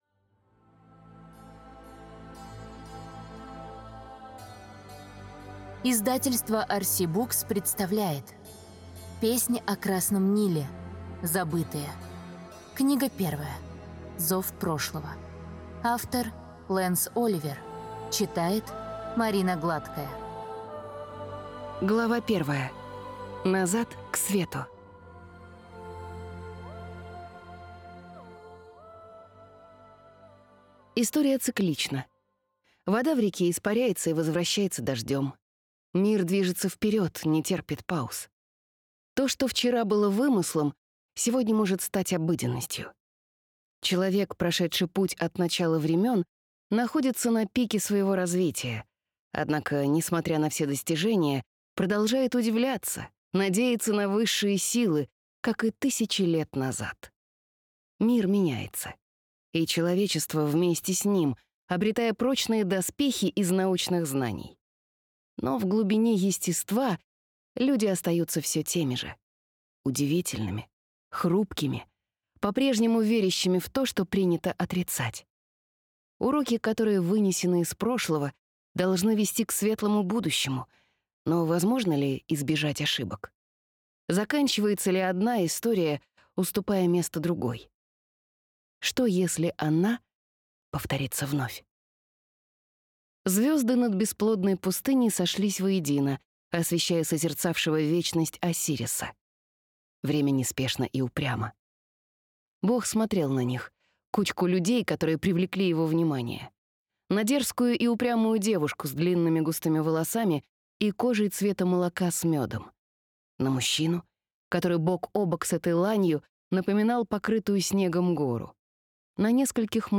Аудиокнига.